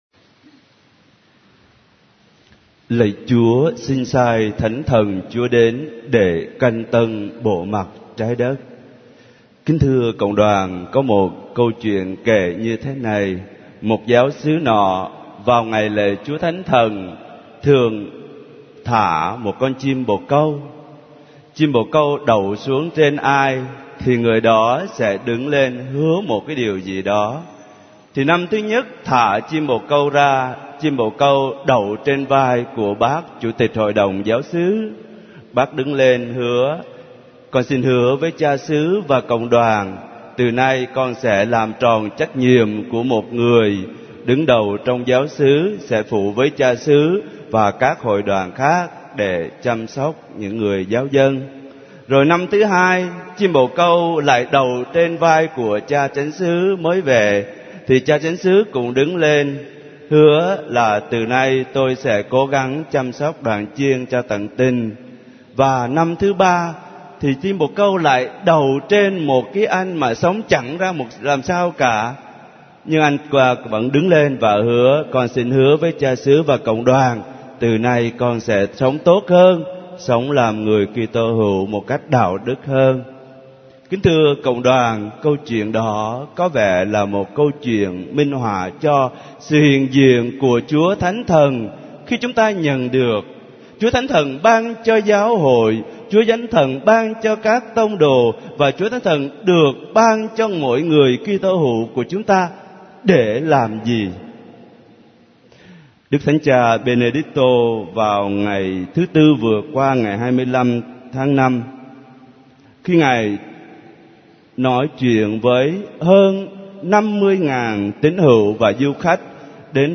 * Thể loại: Nghe giảng